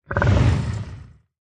assets / minecraft / sounds / mob / zoglin / idle6.ogg